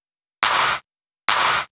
VTDS2 Song Kit 09 Male One Second Clap.wav